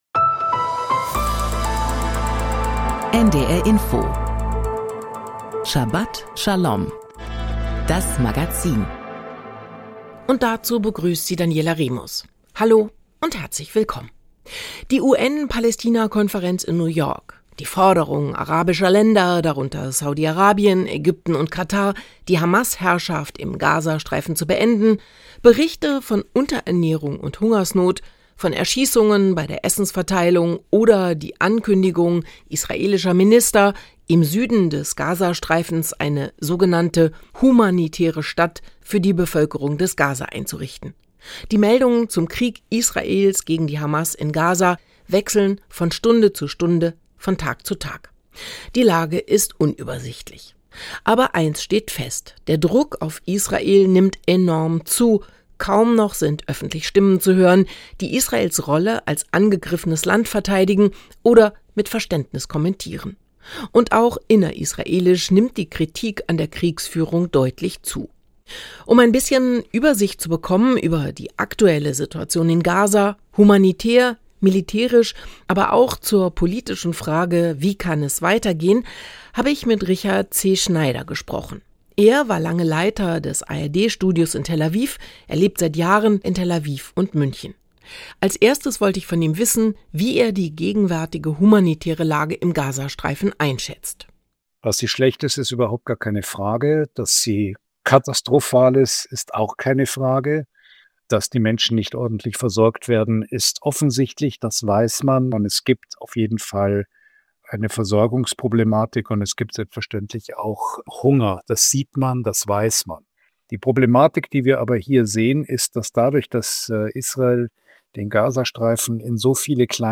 1 Eslovaquia hoy, Magazín sobre Eslovaquia (7.3.2025 16:30) Play Pause 11h ago Play Pause Main Kemudian Main Kemudian Senarai Suka Disukai — Para iniciar nuestro programa de hoy, les ofreceremos la segunda parte de la entrevista con un especialista en etnología y un representante de los gorales, que nos presentarán algo de la historia, características y tareas futuras de este pueblo, ahora que recientemente ha obtenido el reconocimiento como minoría étnica y nacional en Eslovaquia.